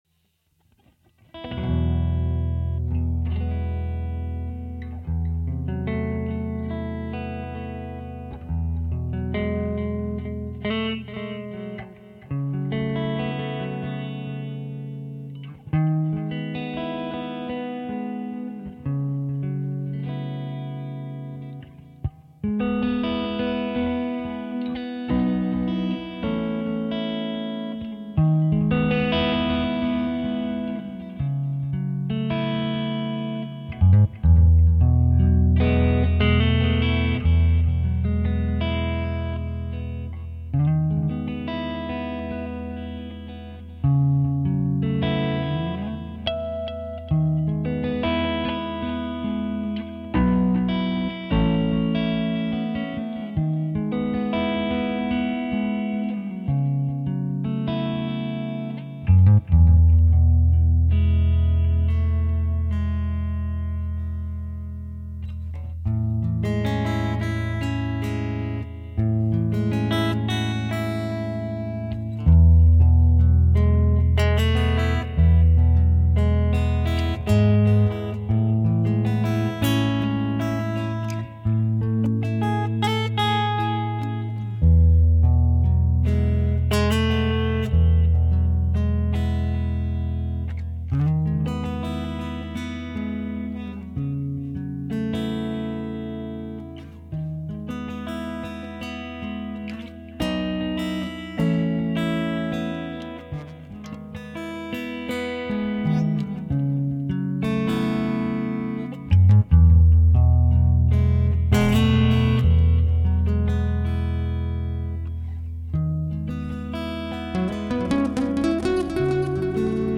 solo guitar piece
We used to play it back in the day when quiet, sort of meditative music may be helpful, in church or elsewhere.
This particular recording is not done with the flute part at all.
as kind of background music.
If you want the chords, contact me and I will put them up in some form – basically it’s a kind of open G#m, F#m, G#m, B, A and later Amaj7 etc.